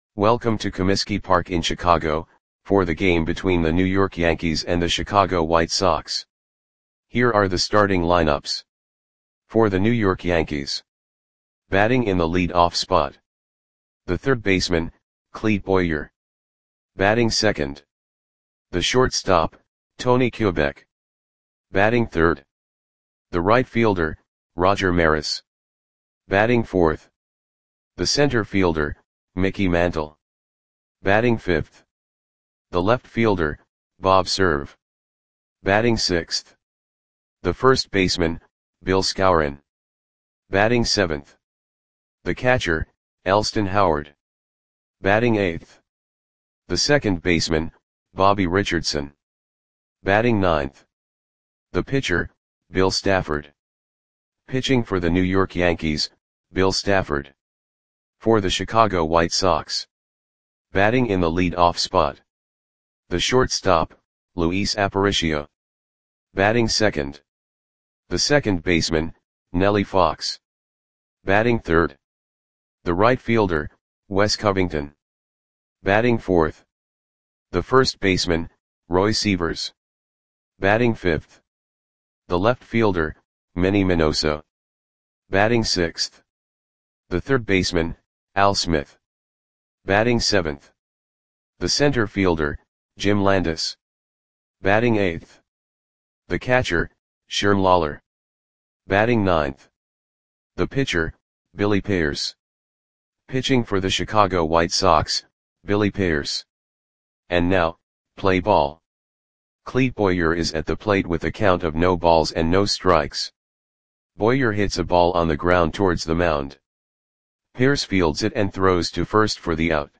Audio Play-by-Play for Chicago White Sox on June 4, 1961
Click the button below to listen to the audio play-by-play.